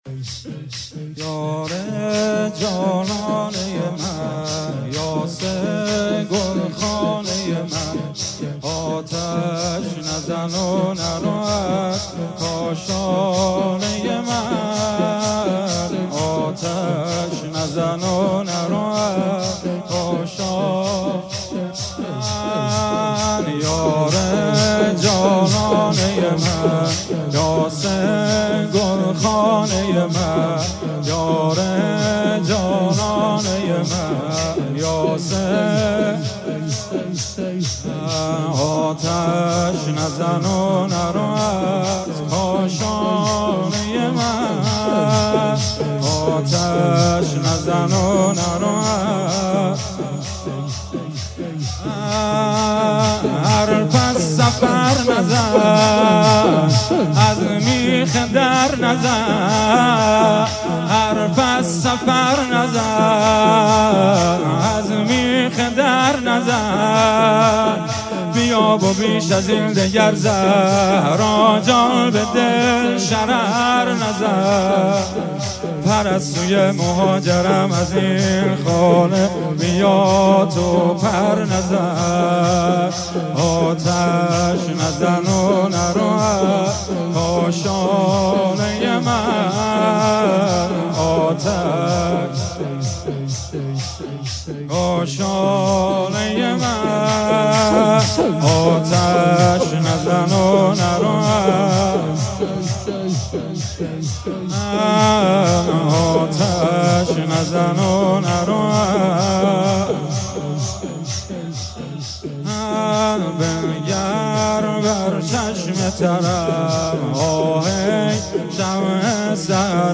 روضه انصارالزهرا سلام الله علیها